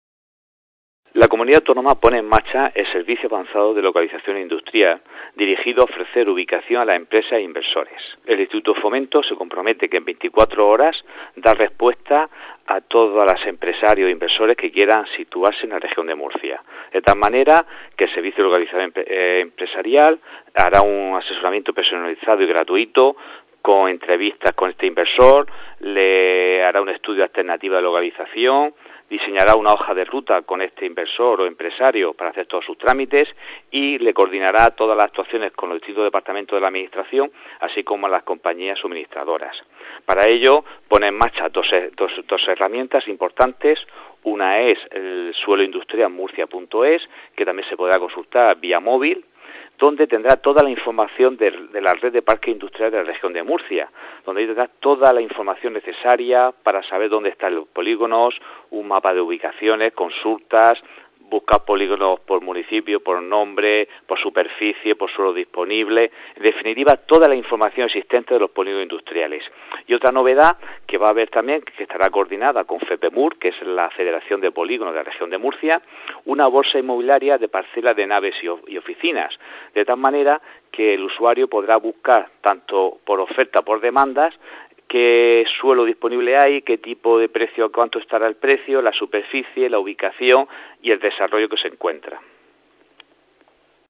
Declaraciones del director del Instituto de Fomento, Juan José Beltrán, sobre el Servicio Avanzado de Localización Industrial para Inversores, 'SALIN'